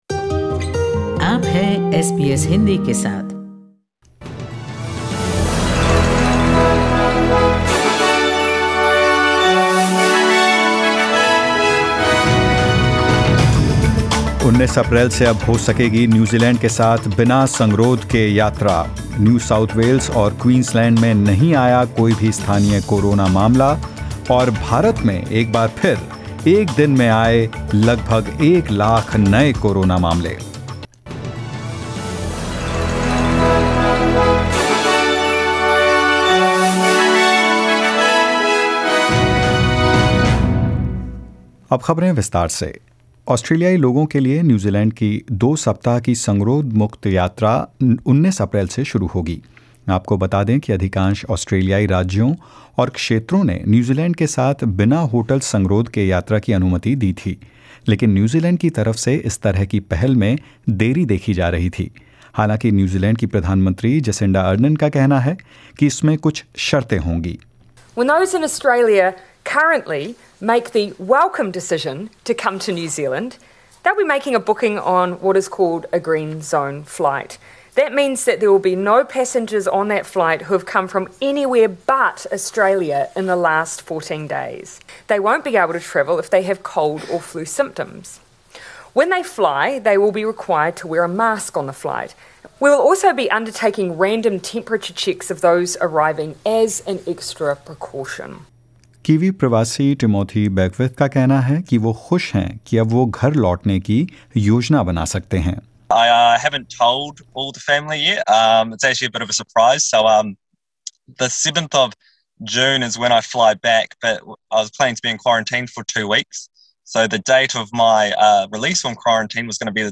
News in Hindi : New Zealand announces trans-Tasman travel bubble